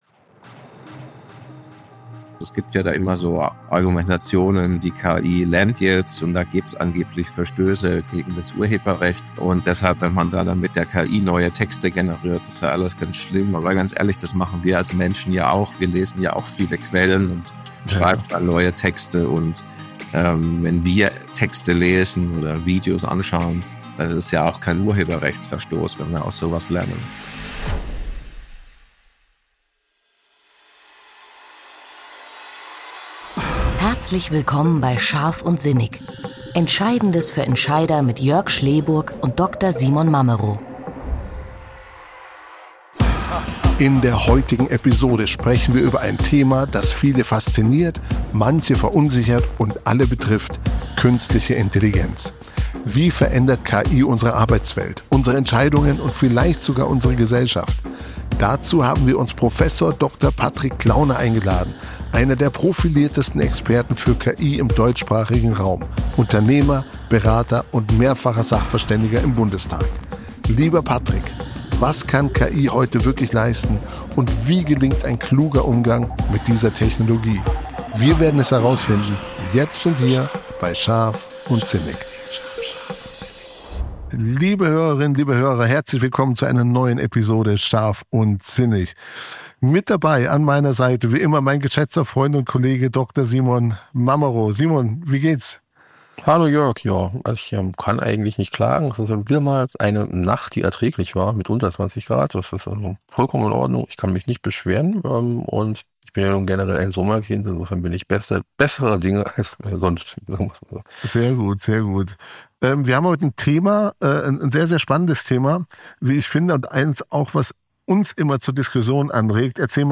SPRECHERIN
EDIT, MIXDOWN & MASTERING